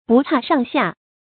不差上下 注音： ㄅㄨˋ ㄔㄚˋ ㄕㄤˋ ㄒㄧㄚˋ 讀音讀法： 意思解釋： 不分高下，差不多 出處典故： 清 曹雪芹《紅樓夢》第80回：「一般是鮮花嫩柳，與眾姊妹 不差上下 。」